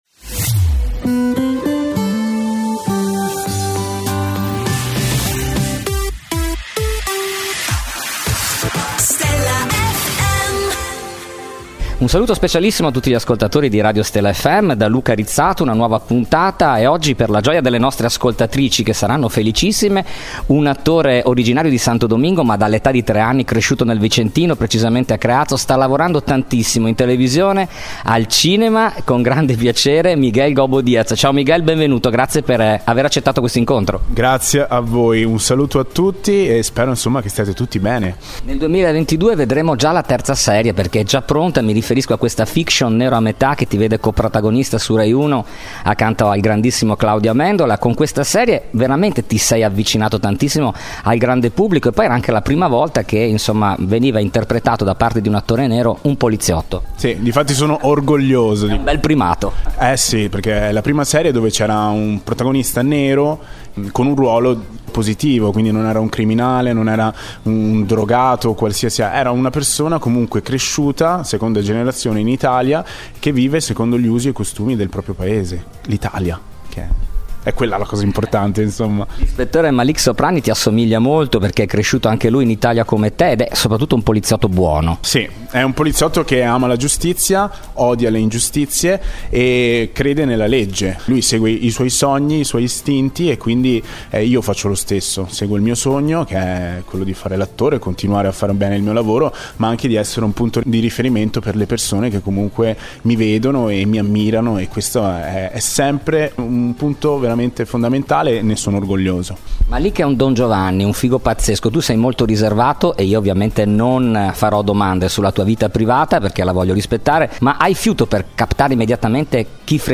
Intervista esclusiva dell’inviato per Stella FM a Miguel Gobbo Diaz.